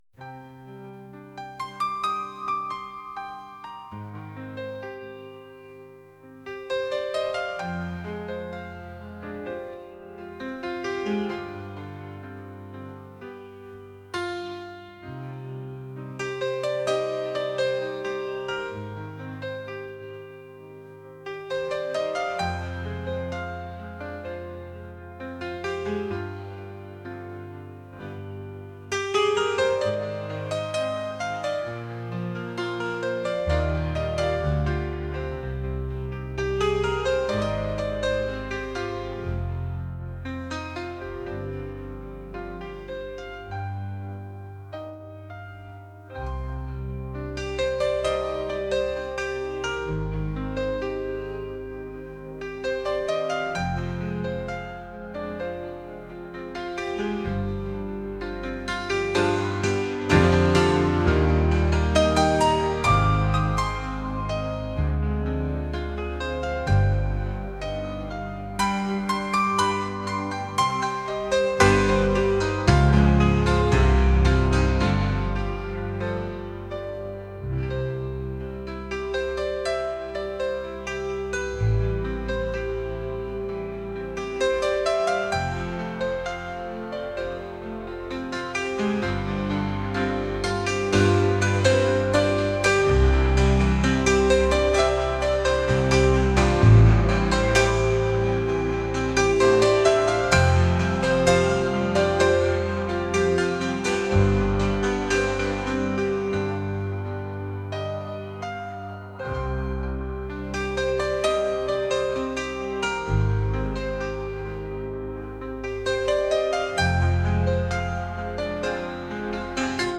pop | acoustic | classical